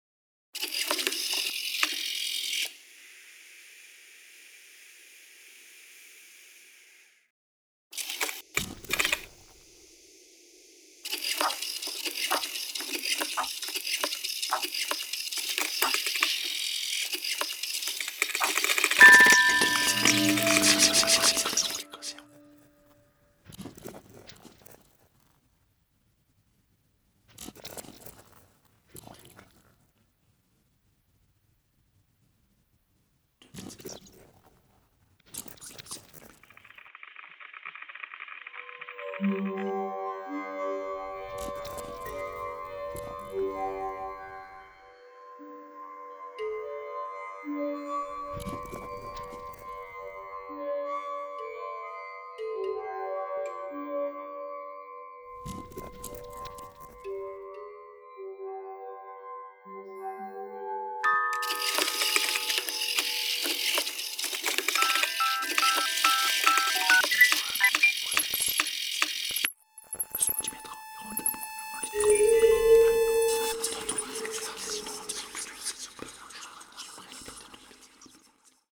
Sound installation